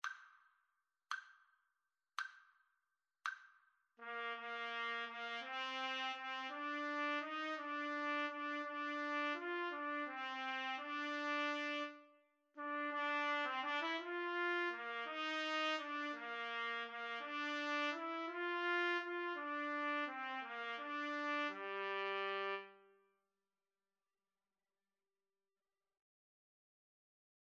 One in a bar . = 56
3/4 (View more 3/4 Music)
G minor (Sounding Pitch) A minor (Trumpet in Bb) (View more G minor Music for Trumpet Duet )
Trumpet Duet  (View more Easy Trumpet Duet Music)
Traditional (View more Traditional Trumpet Duet Music)